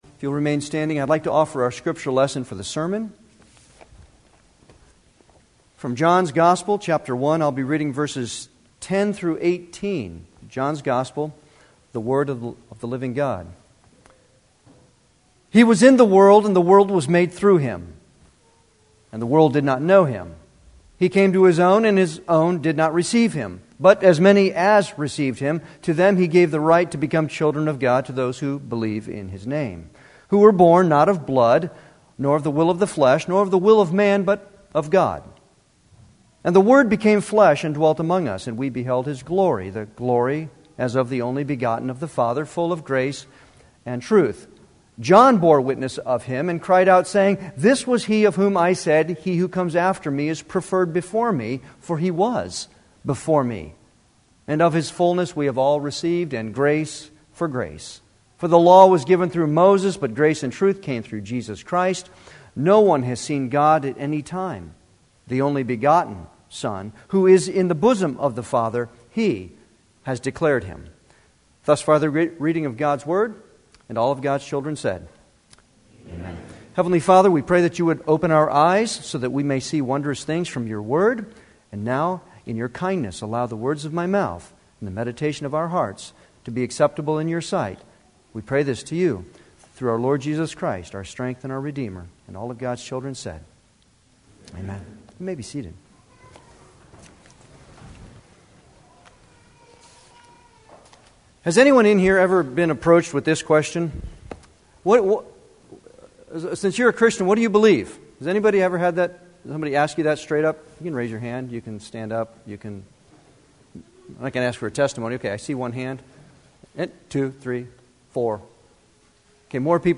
Series: Advent Sermons
Service Type: Sunday worship